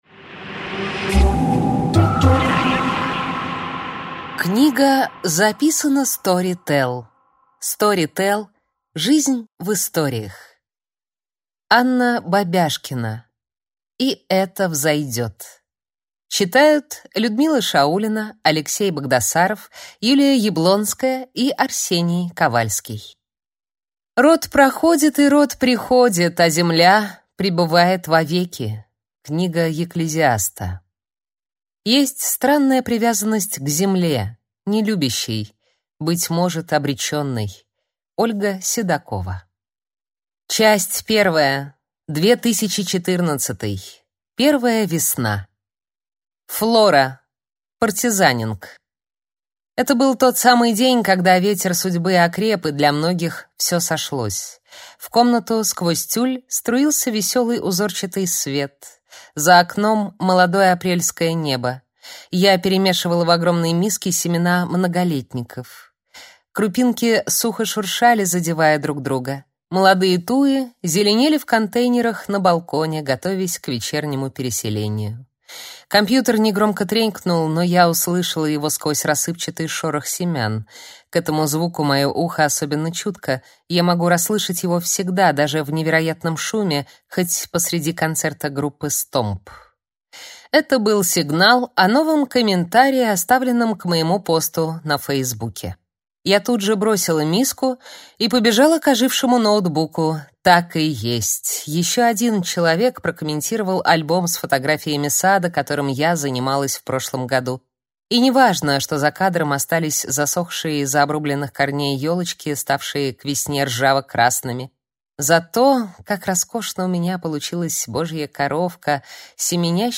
Аудиокнига И это взойдет | Библиотека аудиокниг